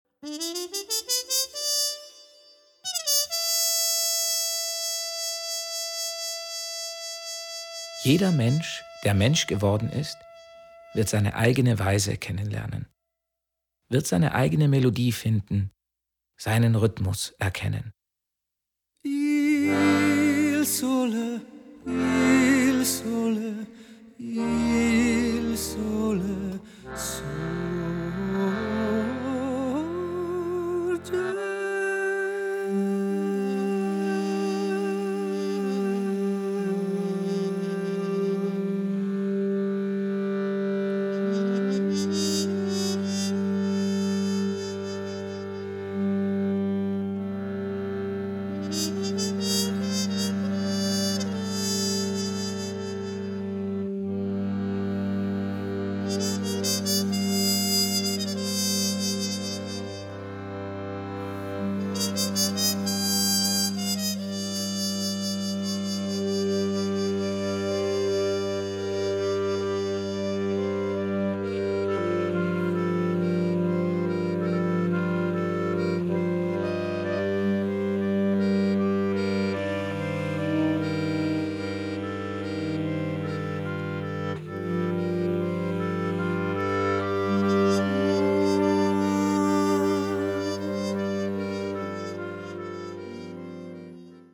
Bassgitarren
Background Gesang